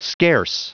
Prononciation du mot scarce en anglais (fichier audio)
Prononciation du mot : scarce